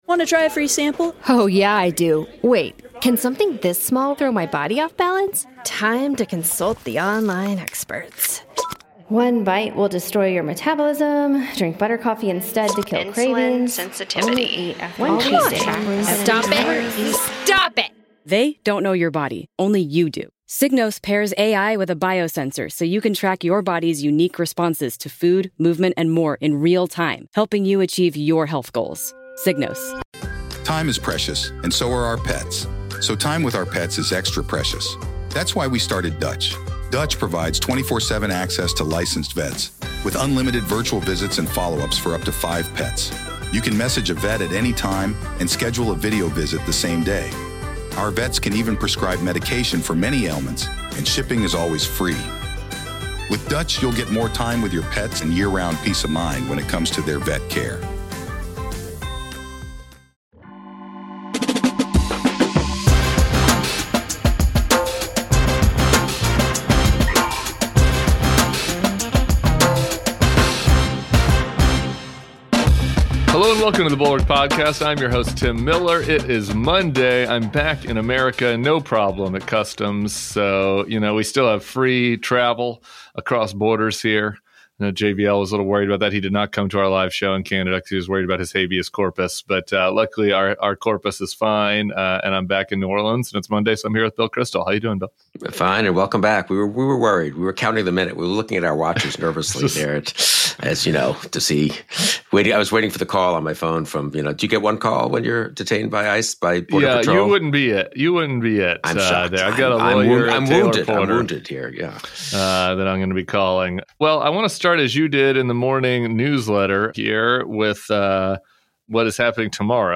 Bill Kristol joins Tim Miller .